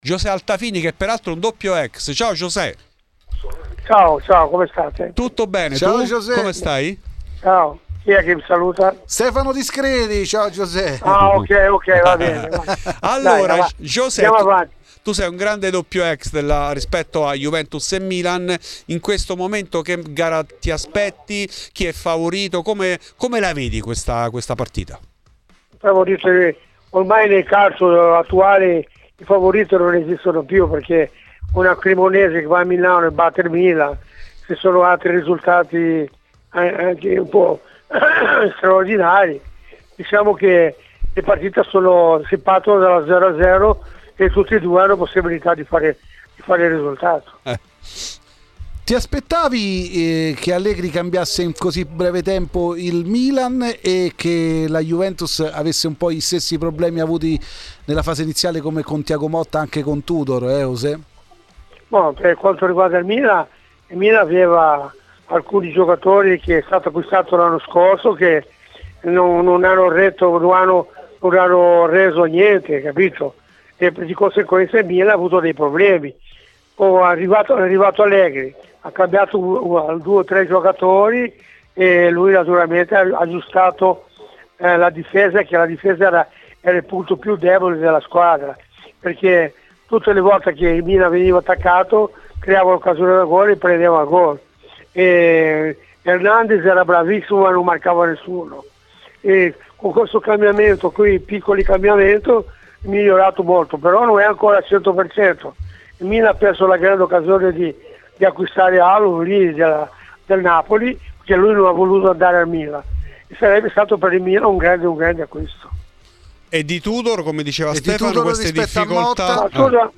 Ospite d'eccezione a Fuori di Juve, trasmissione di Radio Bianconera: è intervenuto infatti Josè Altafini, per parlare di Juve-Milan, sfida che lui viva da doppio ex: "Ormai nel calcio attuale non esistono più i favoriti, abbiamo visto la Cremonese che ha battuto il Milan a San Siro.